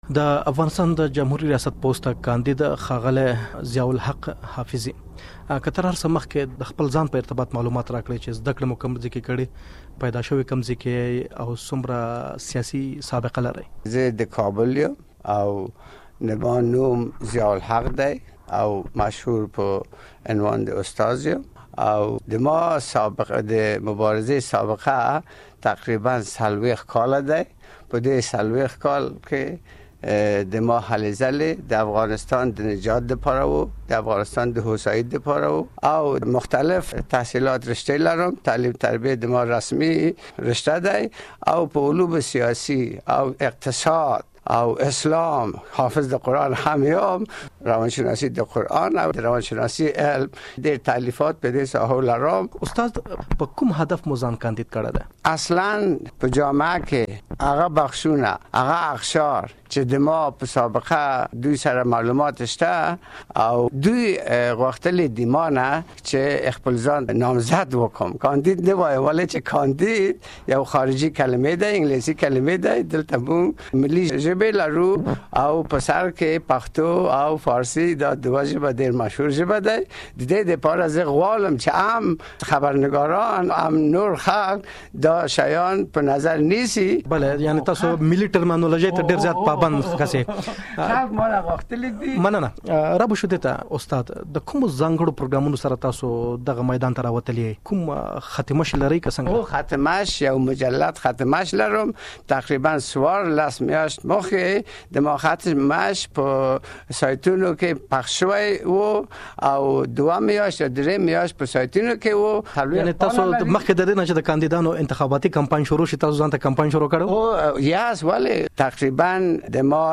ځانګړې مرکه واورﺉ